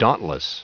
Prononciation du mot dauntless en anglais (fichier audio)
Prononciation du mot : dauntless
dauntless.wav